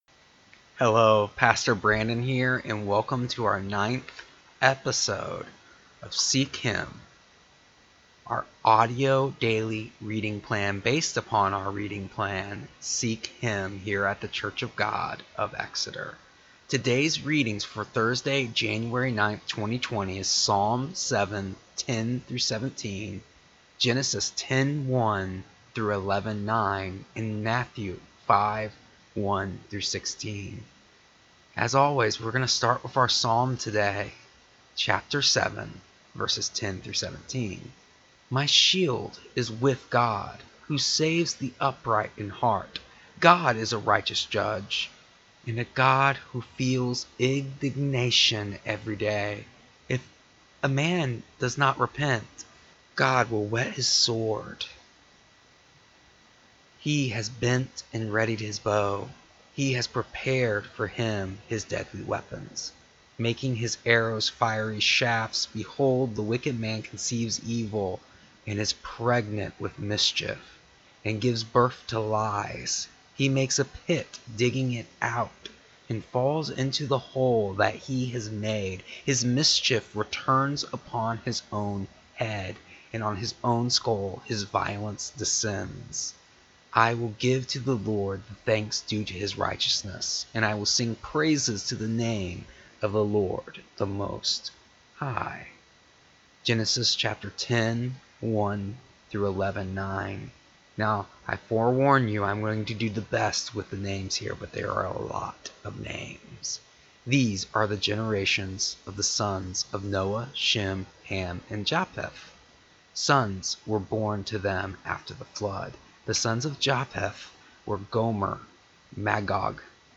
Our readings today were: Psalm 7:10-17 Genesis 10:1-11:9 Matthew 5:1-16